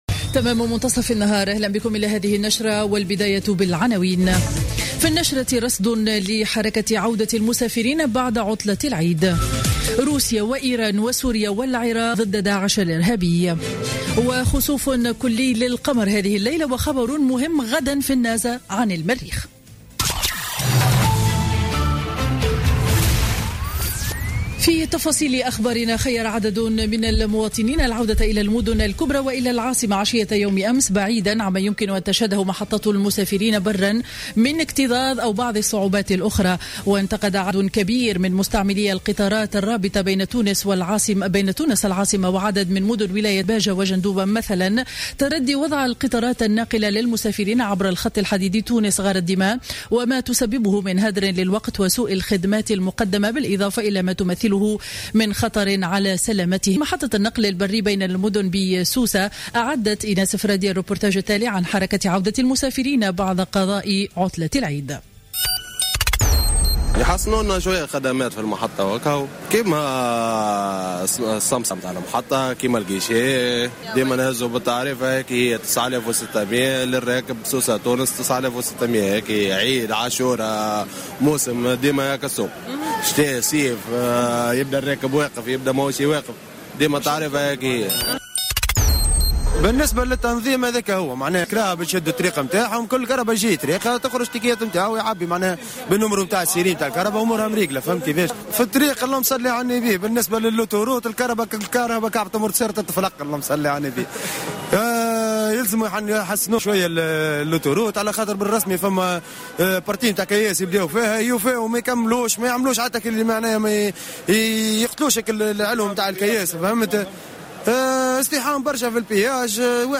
نشرة أخبار منتصف النهار ليوم الأحد 27 سبتمبر 2015